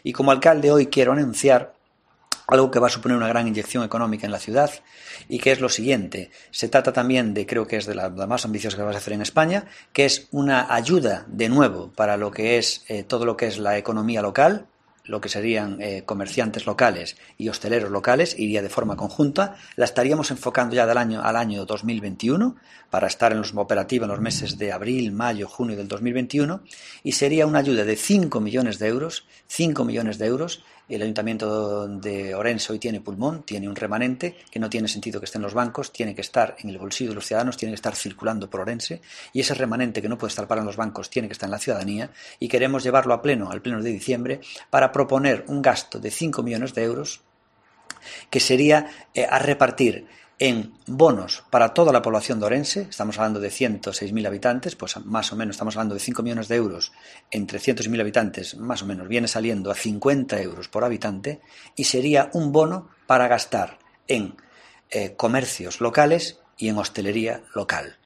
Declaraciones del alcalde de Ourense, Gonzalo Pérez Jácome